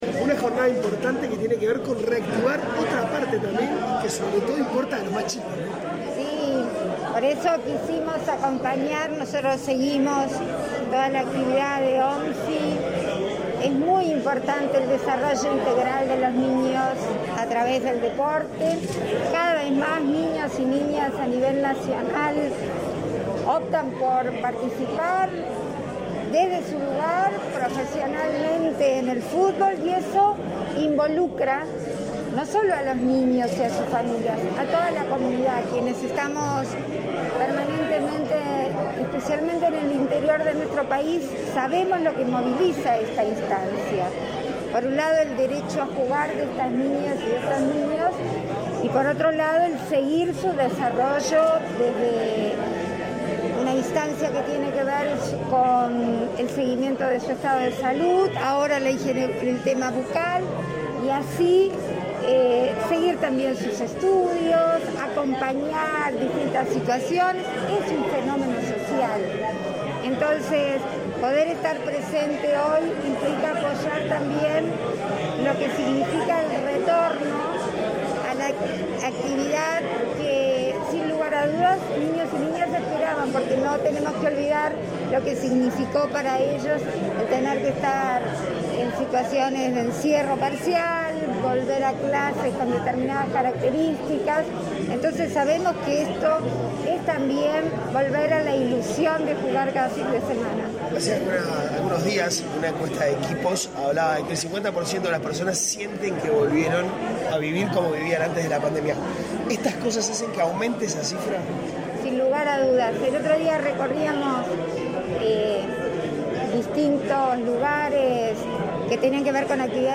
Declaraciones a la prensa de la vicepresidenta de la República, Beatriz Argimón
Declaraciones a la prensa de la vicepresidenta de la República, Beatriz Argimón 15/03/2022 Compartir Facebook X Copiar enlace WhatsApp LinkedIn Tras participar en el lanzamiento de la temporada 2022 de la Organización Nacional de Fútbol Infantil, este 15 de marzo, la vicepresidenta de la República, Beatriz Argimón, efectuó declaraciones a la prensa.
Argimon prensa.mp3